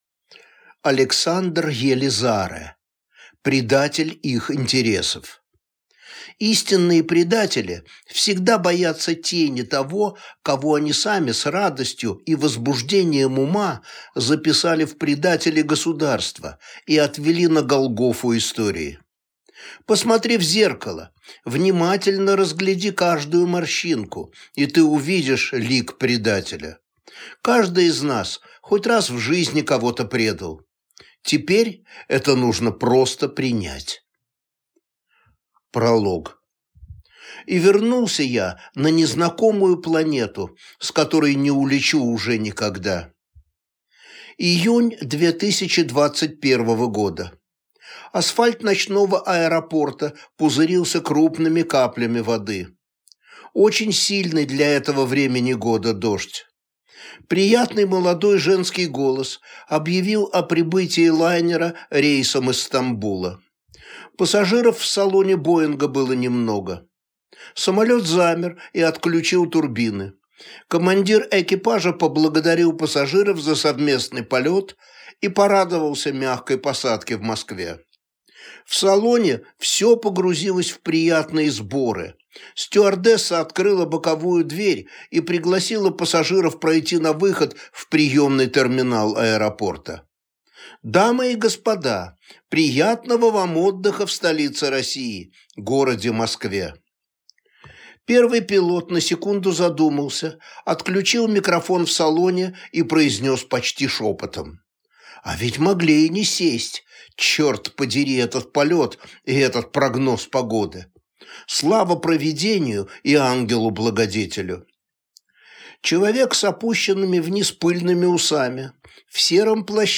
Аудиокнига Предатель их интересов | Библиотека аудиокниг